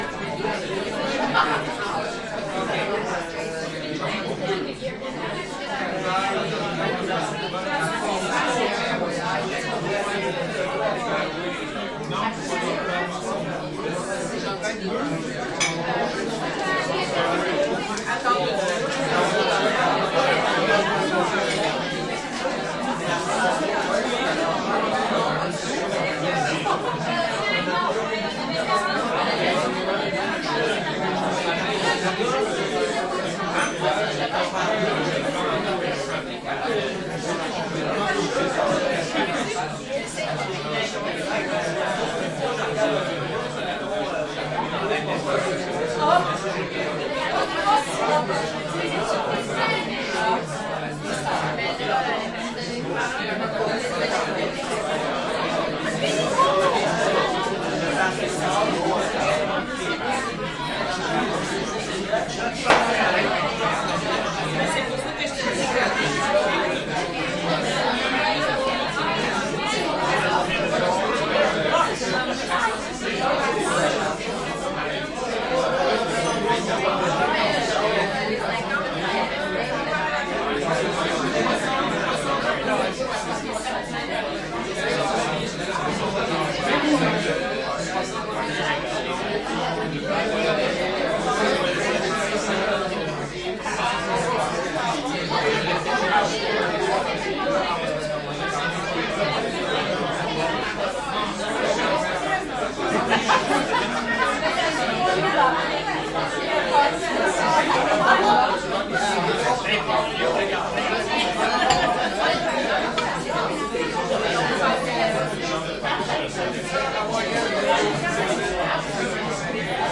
蒙特利尔 " 人群中的中型厨房派对 Walla 蒙特利尔，加拿大
Tag: 厨房 蒙特利尔 派对 INT 人群 介质 加拿大 沃拉